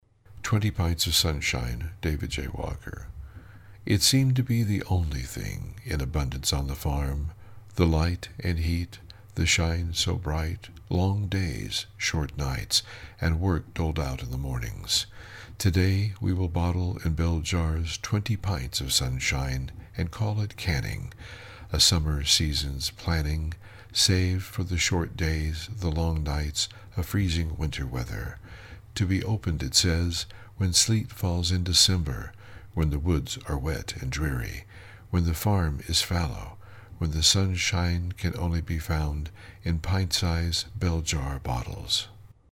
I heard your voice, mesmerising.